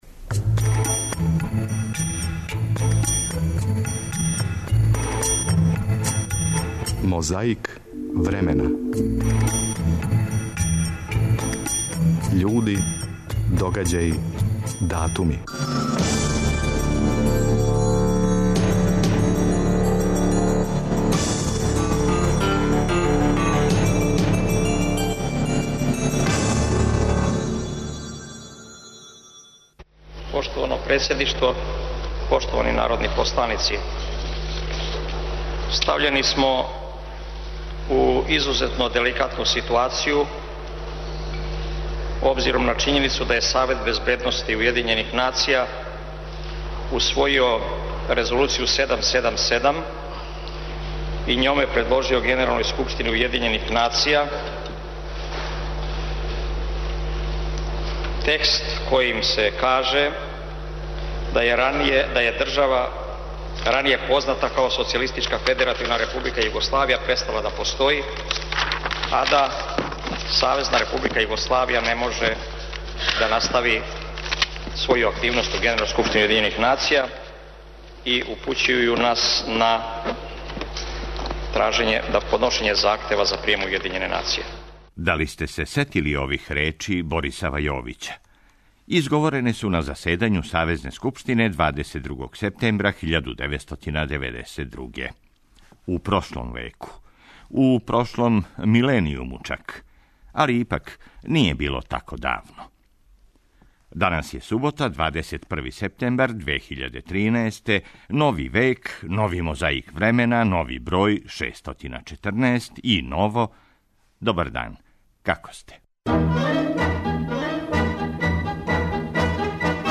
Присећање на време прошло почињемо речима Борисава Јовића. Изговорене су на заседању Савезне скупштине 22. септембра 1992. године, у прошлом веку, у прошлом миленијуму чак, али ипак није било тако давно.
Подсећа на прошлост (културну, историјску, политичку, спортску и сваку другу) уз помоћ материјала из Тонског архива, Документације и библиотеке Радио Београда.